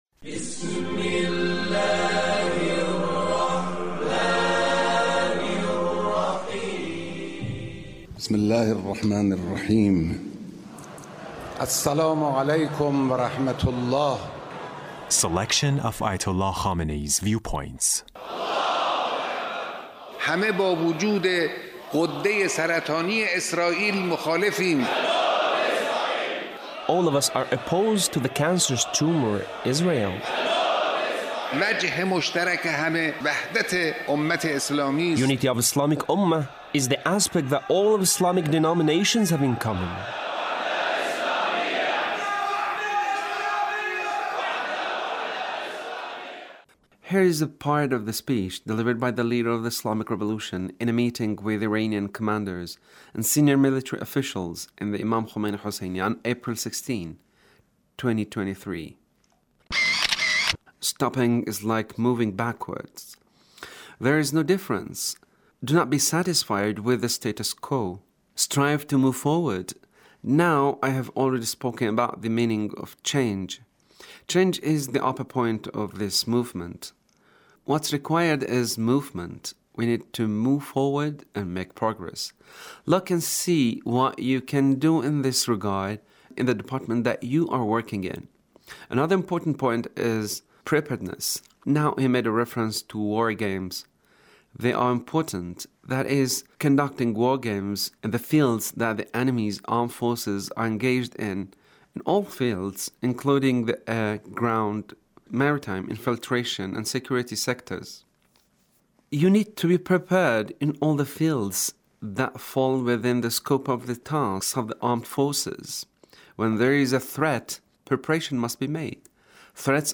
Leader's Speech (1721)